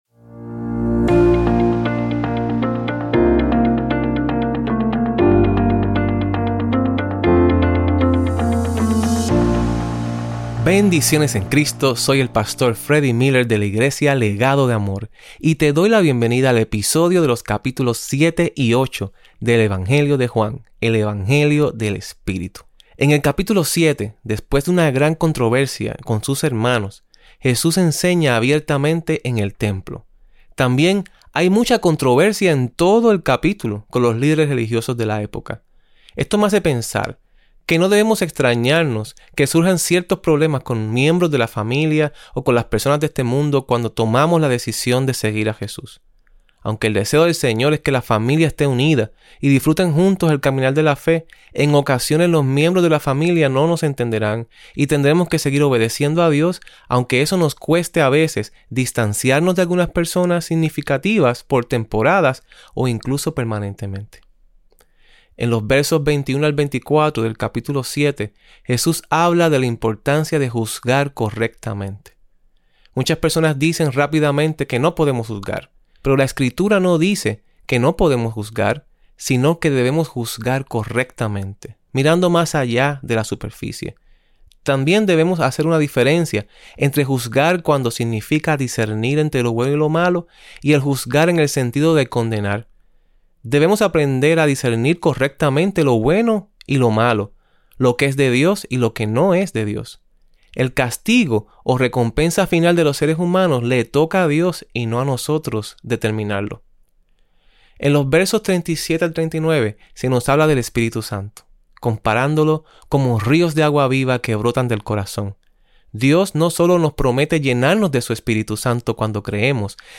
Estudio-de-Juan-7-y-8.mp3